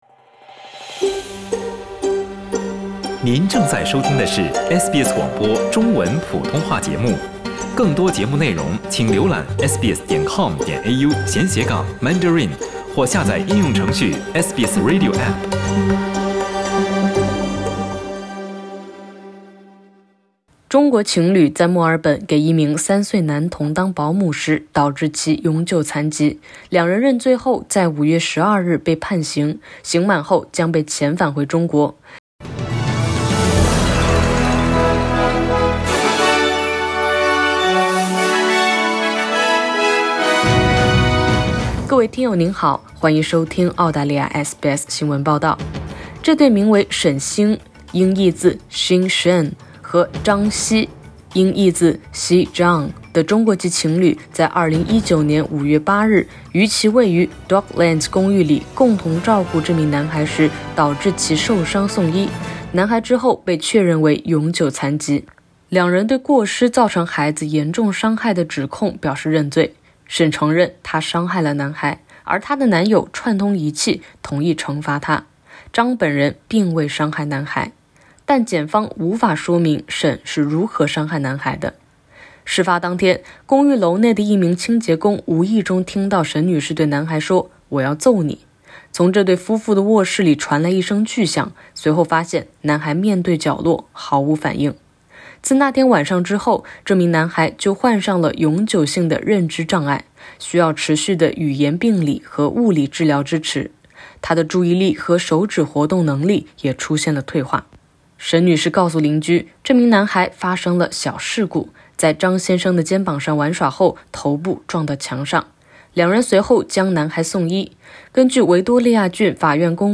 这对中国情侣在墨尔本给一名3岁男童当保姆时导致其永久残疾，两人认罪后被判刑，刑满后将被遣返回中国。(点击上图收听报道)